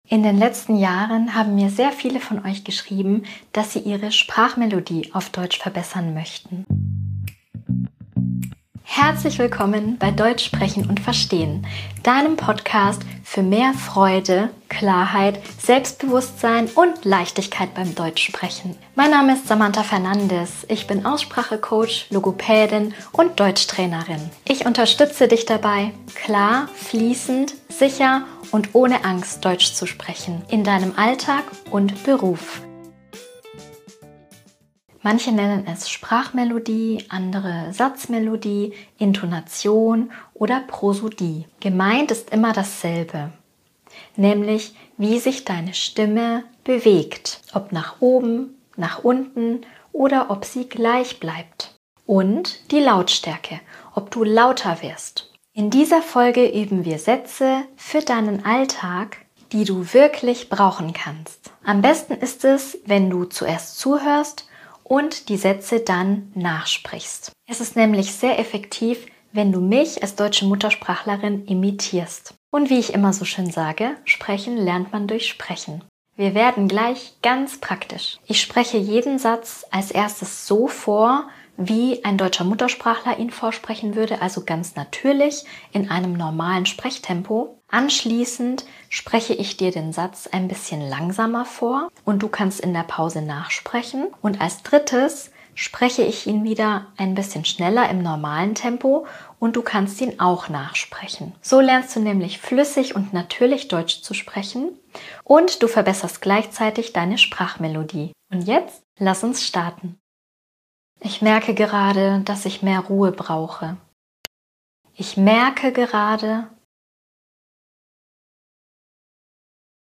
sprich in den Pausen laut nach.